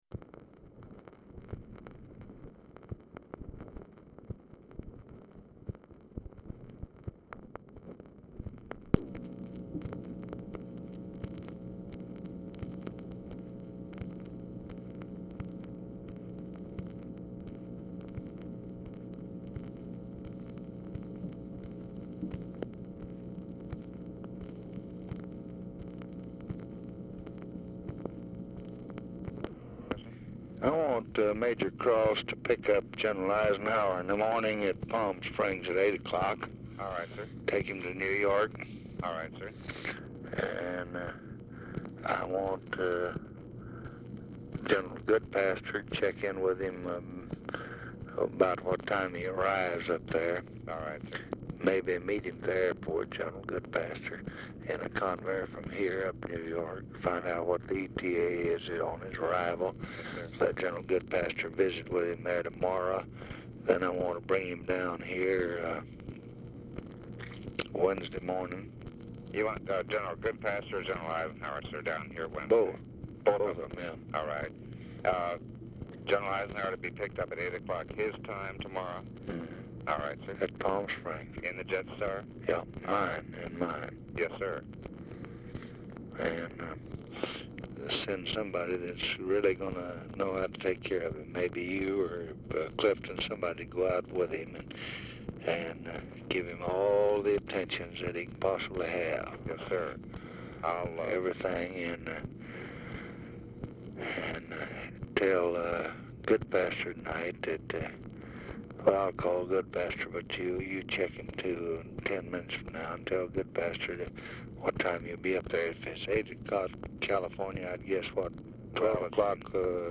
Format Dictation belt
Specific Item Type Telephone conversation